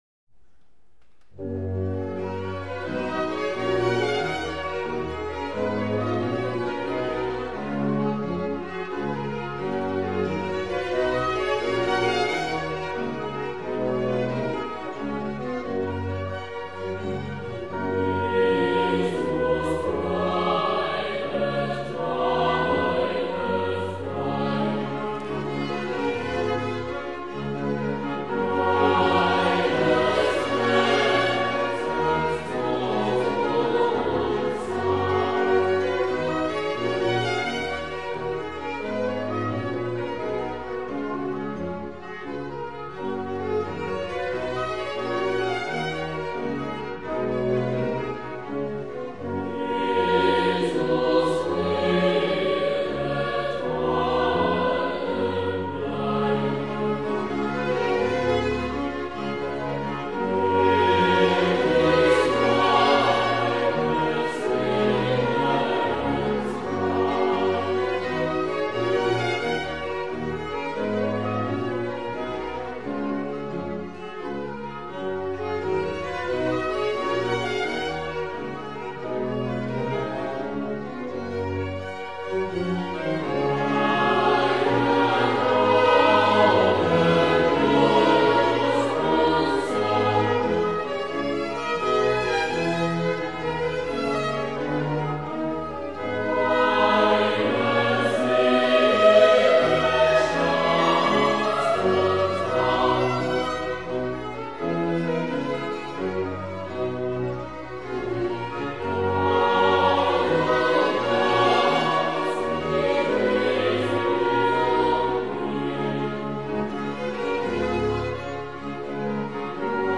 Jesus bleibet meine Freude de Johann Sebastian Bach par le Tölzer Knabenchor
tocc88lzer-knabenchor-jesus-bleibet-meine-freude.mp3